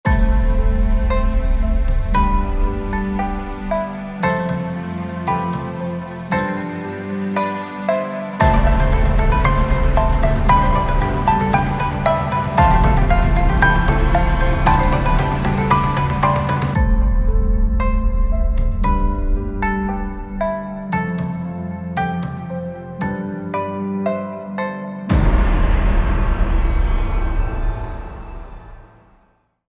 直观的调节压缩，声音的明亮度
IX Sounds Strings of the Orient Solo Pipa 是一款虚拟乐器，它可以模拟中国古老的弹拨乐器——琵琶的声音和演奏技巧。
- 独特的音色和音准，可以表现出琵琶的魅力和多样性。
- 多层动态，可以根据演奏强度调整音量和音色。
- 定制的混响，可以模拟出不同的空间效果。
IX-Sounds-Strings-of-the-Orient-Solo-Pipa.mp3